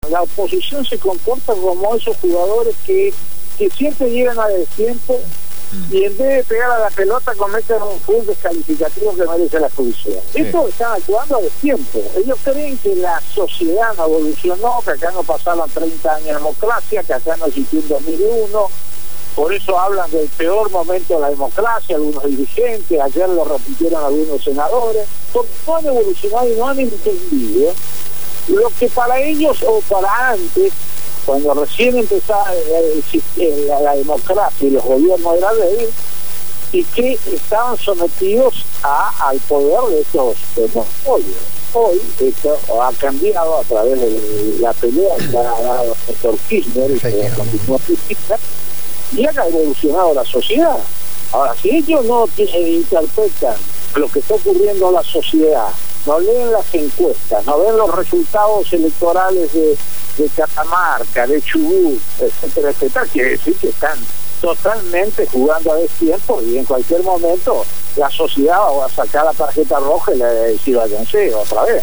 «La oposición se comporta como esos jugadores que no llegan a tiempo y en cualquier momento la sociedad les va a sacar la tarjeta roja» fueron las palabras del Senador Nacional por la provincia de Misiones Eduardo Enrique Torres en una entrevista realizada por el programa «Cambio y futuro en el aire» (Jueves de 20 a 22hs.) por Radio Gráfica.